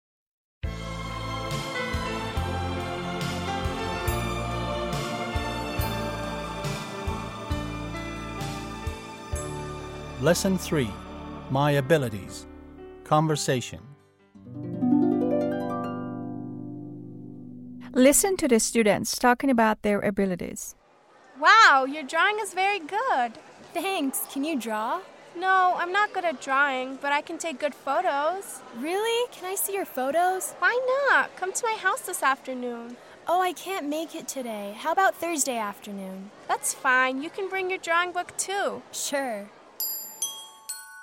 8-L3-Conversation